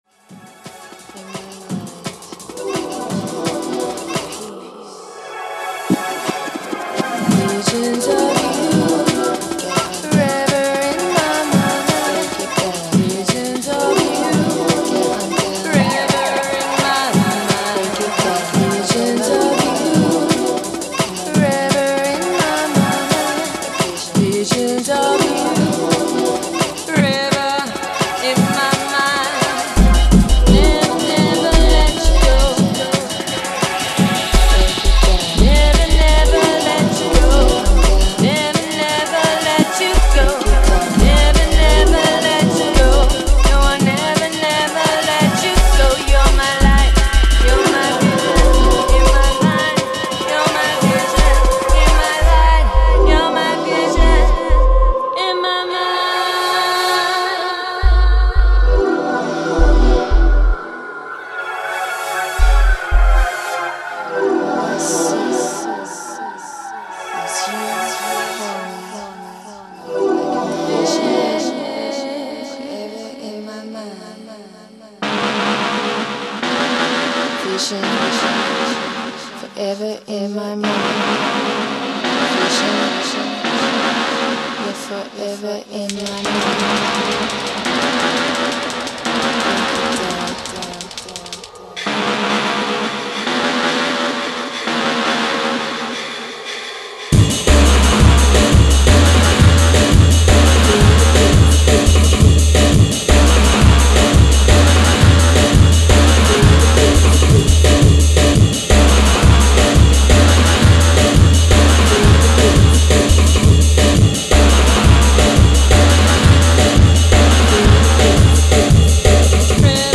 futuristic dark sounds and the early Techstep scene in DNB